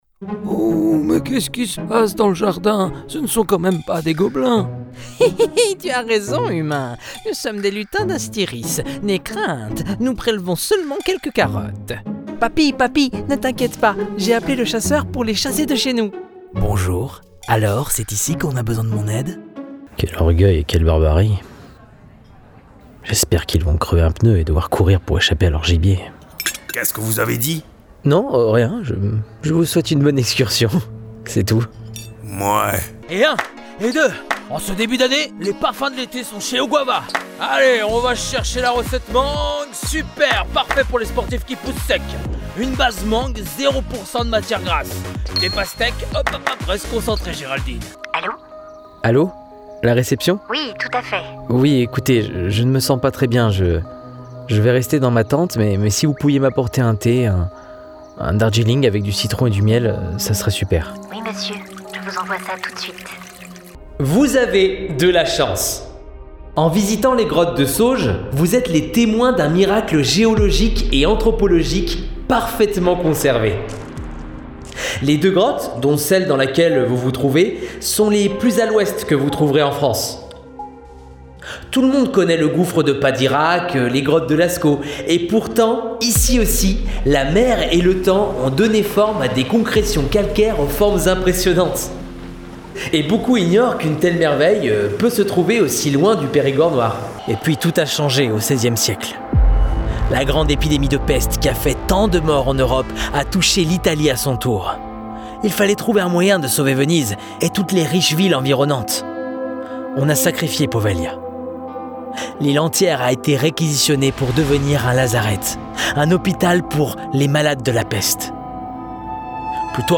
Voix off
- Ténor